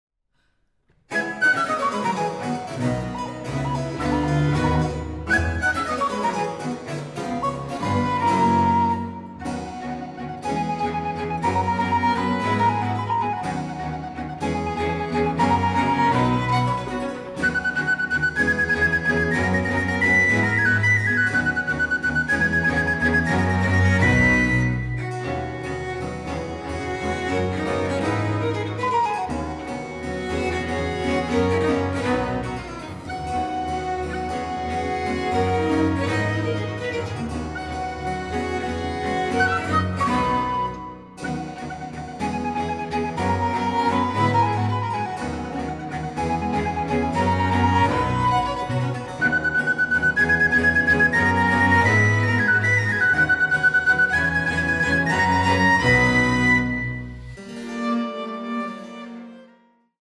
Flöte